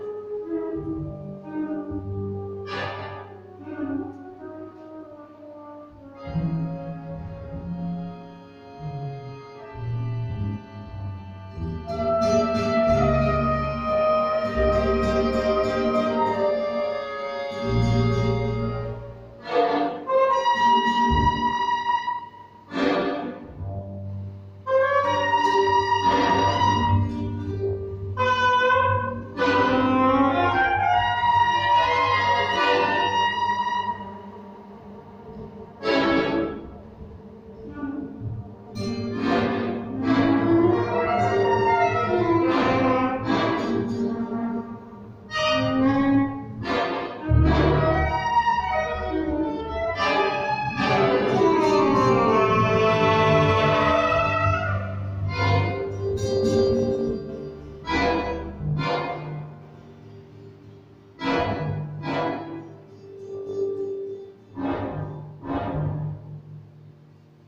Improvisation musicale au Val d'Allos
Partager la scène avec cinq musiciens de jazz qui improviseraient sur mes collections multi-sensorielles : voilà la proposition d'expérimentation artistique qui me fut proposée courant décembre dernier.